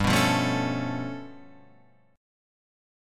G9b5 chord